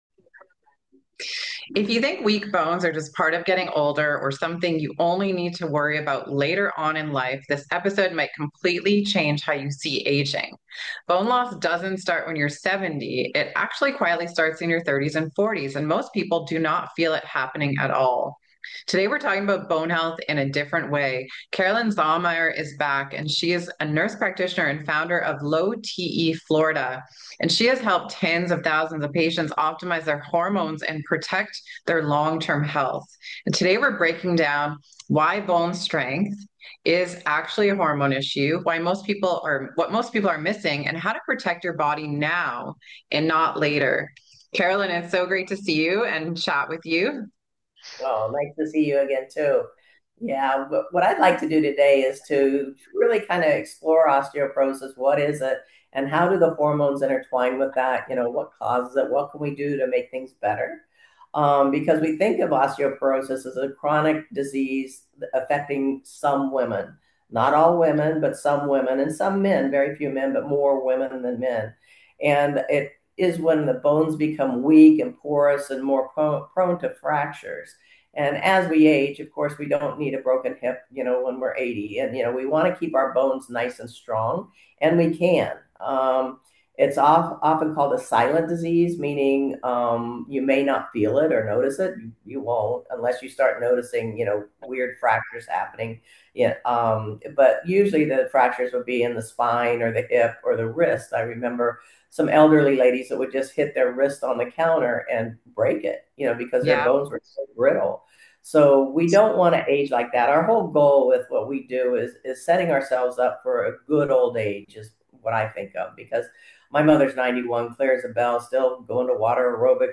for a conversation about how hormones influence bone health and what women can do now to support strong bones long term.